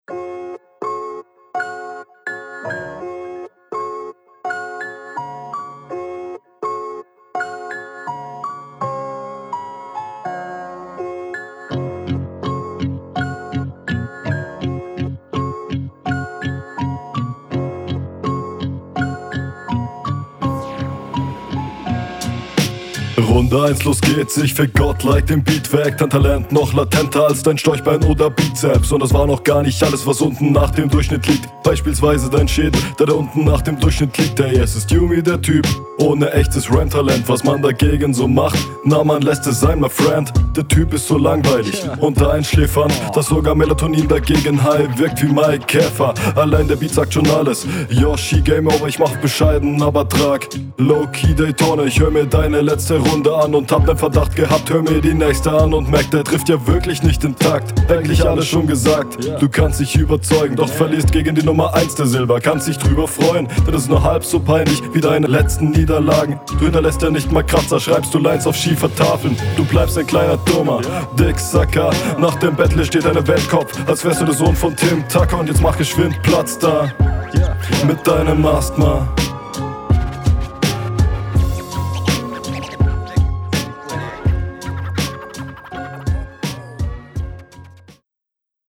Flow: Jo der Anfang ganz stabil, aber du ballerst zu viele Silben in den Takt, …
Flow: flow schon cool und Routinier find ich echt nice, vielleicht insgesamt bisschen monoton aber …
Flow: Stabiler Flow, der allerdings ab der Mitte ziemlich off ist an einigen Stellen. Das …